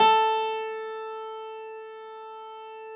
53h-pno13-A2.aif